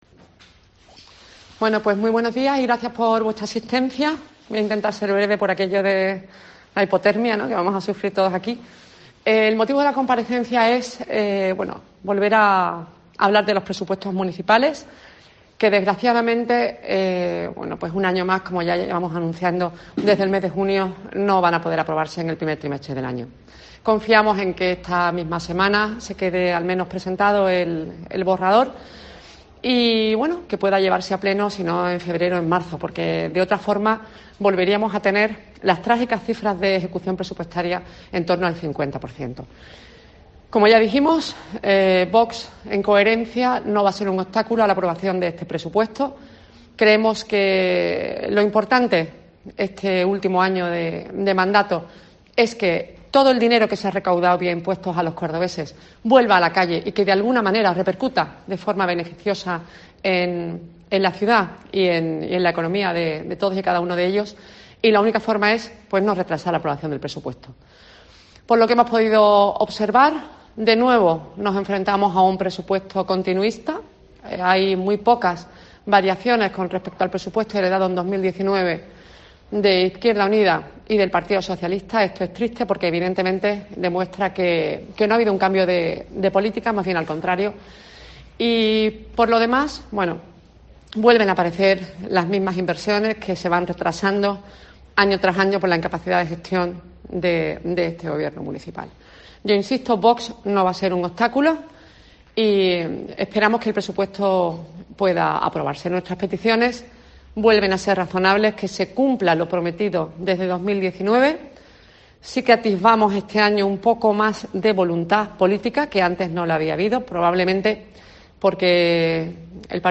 Escucha a Paula Badanelli sobre la ejecución de los presupuestos en Córdoba